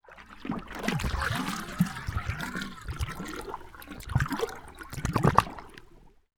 Water_35.wav